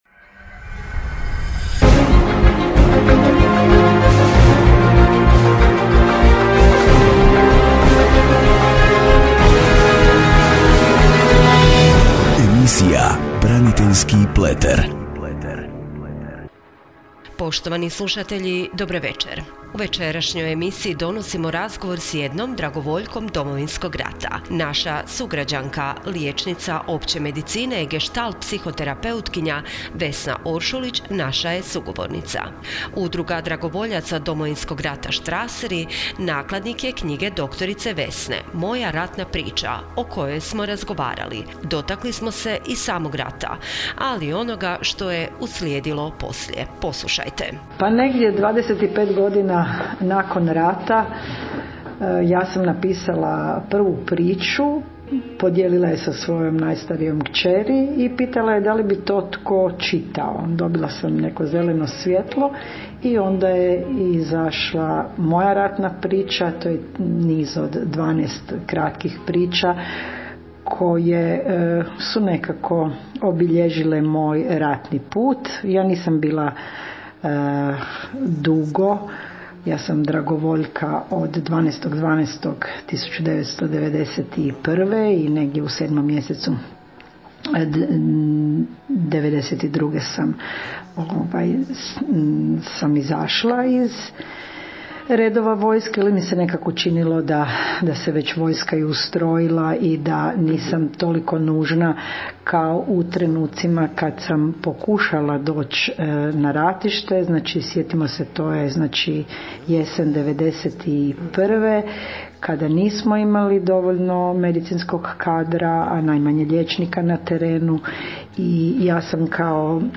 Radijska emisija: “BRANITELJSKI PLETER”